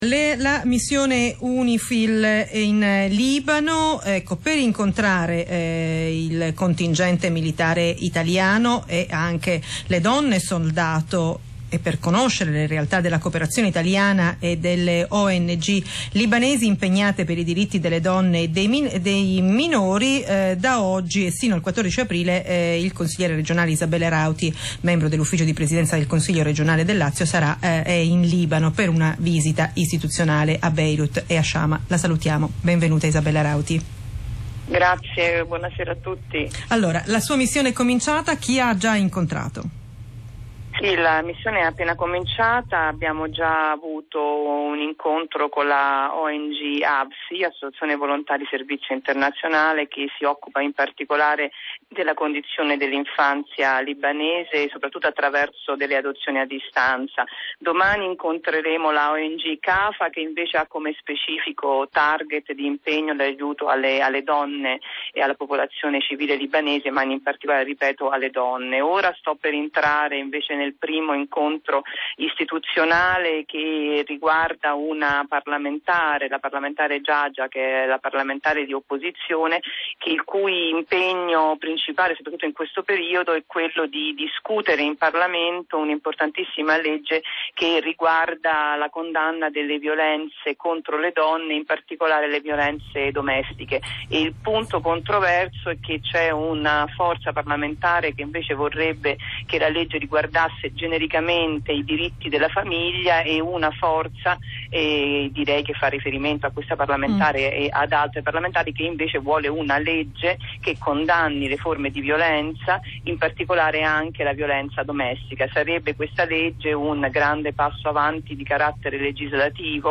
Radio1 Rai – Isabella Rauti in diretta telefonica dal Libano, ospite della trasmissione “Baobab” [FM 89.7]